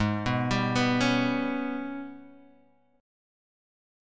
AbmM7bb5 chord